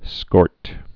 (skôrt)